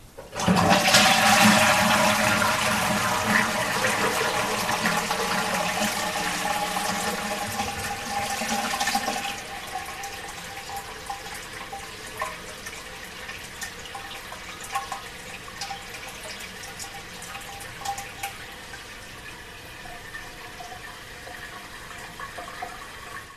Public domain sound effects
flush.mp3